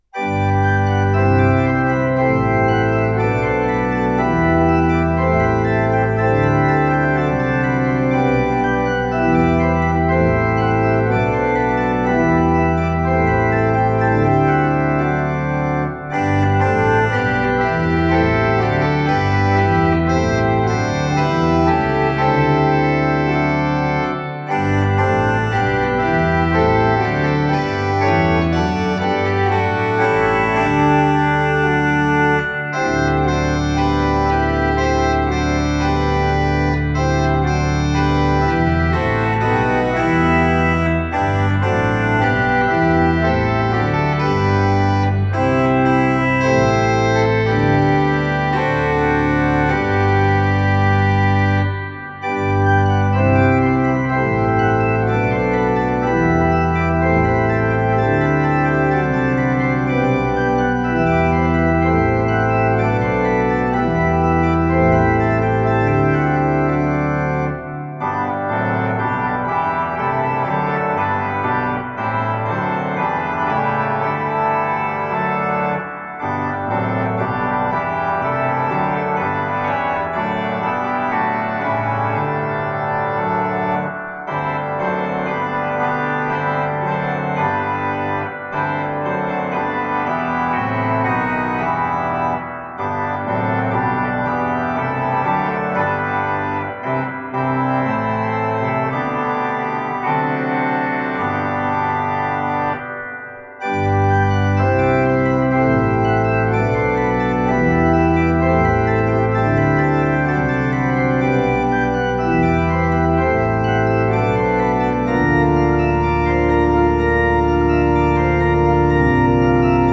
I realized, however, that many congregations in the church do not sing this hymn often, so I needed to introduce the melody in its original form before embellishing it too much. After that, I decided to set the tune in four part harmony before introducing the trumpet tune.
This arrangement is intended to be a postlude.